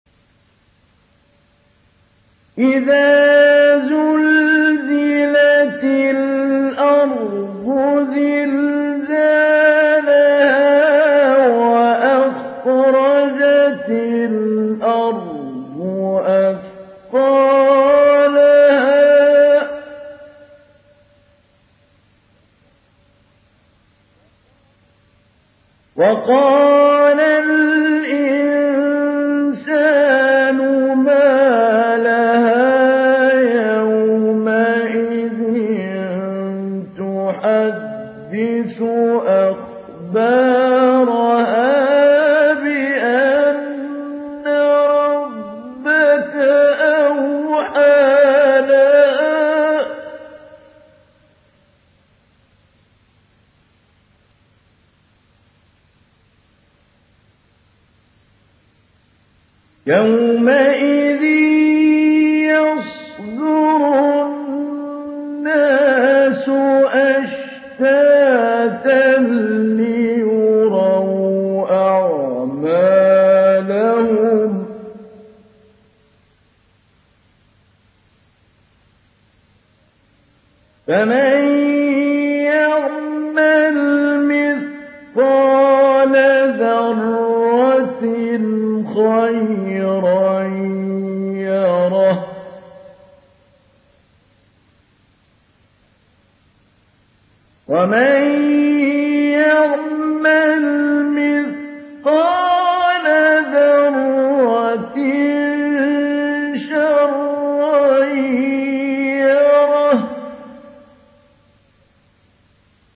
Zelzele Suresi İndir mp3 Mahmoud Ali Albanna Mujawwad Riwayat Hafs an Asim, Kurani indirin ve mp3 tam doğrudan bağlantılar dinle
İndir Zelzele Suresi Mahmoud Ali Albanna Mujawwad